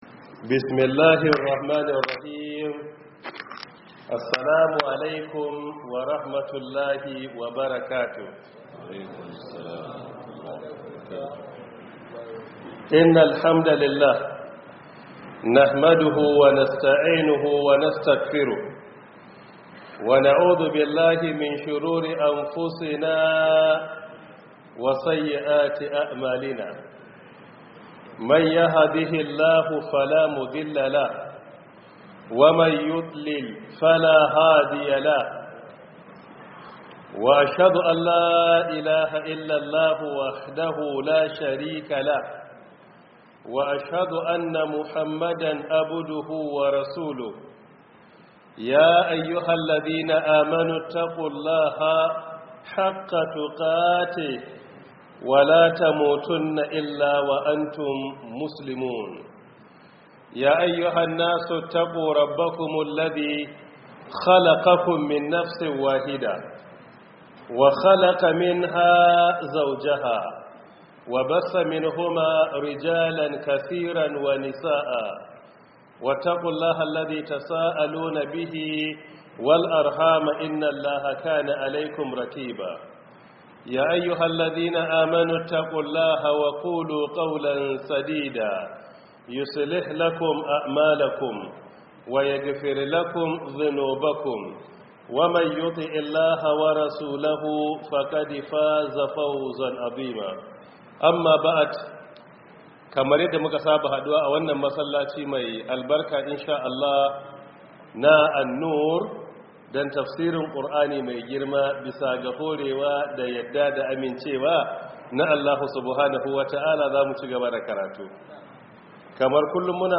Download Audio From Book: 1447/2026 Ramadan Tafsir